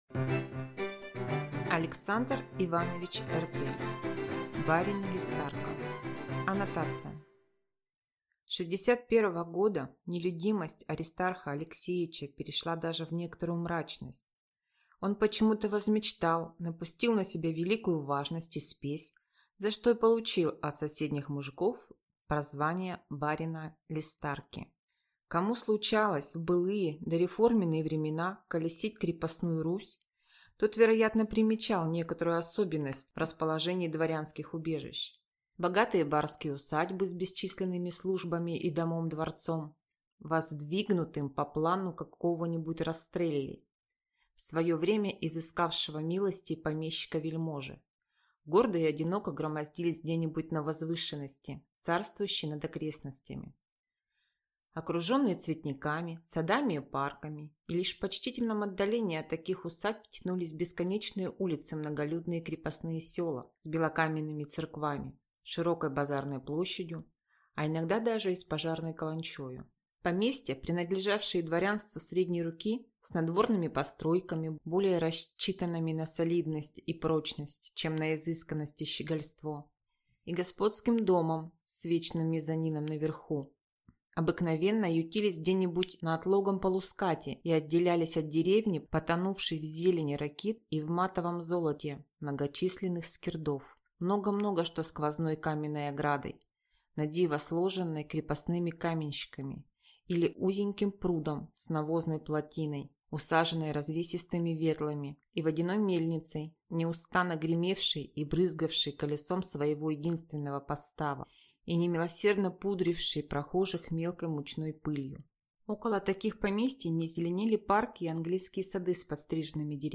Аудиокнига Барин Листарка | Библиотека аудиокниг